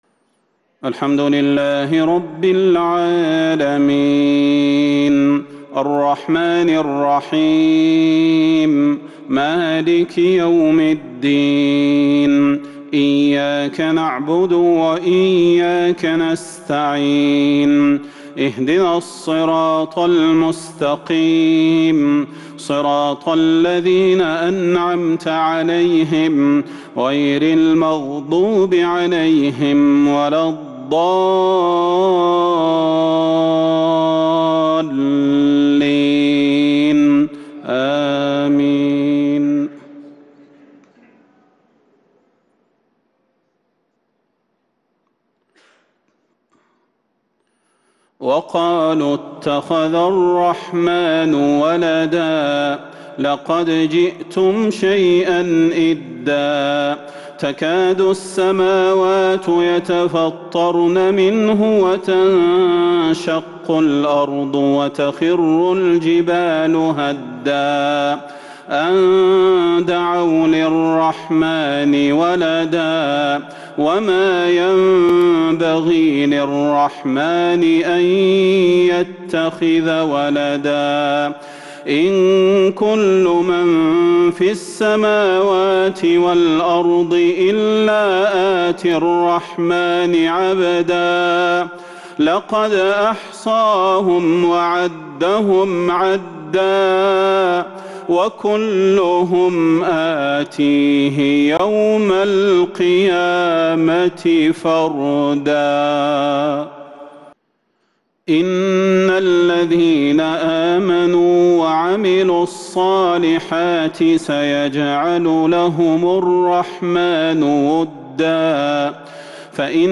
عشاء الخميس 22 شوال 1442هــ من سورة مريم | Isha prayer from Surat Maryam 3-6-2021 > 1442 🕌 > الفروض - تلاوات الحرمين